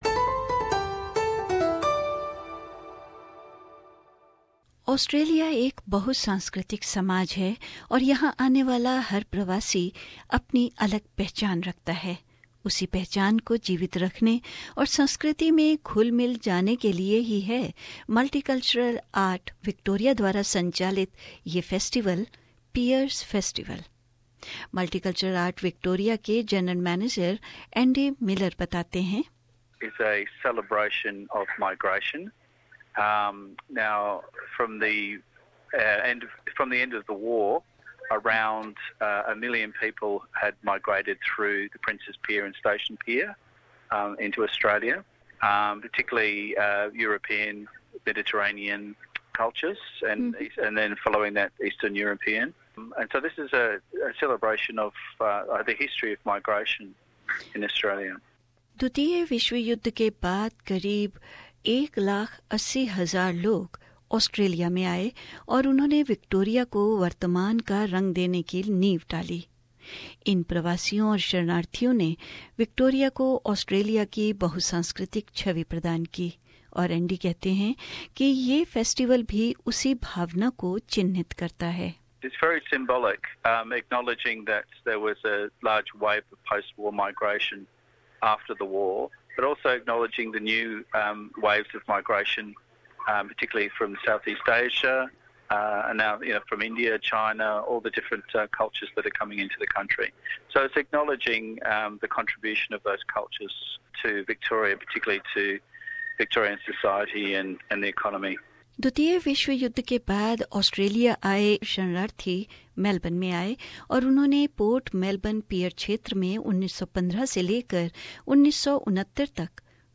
A report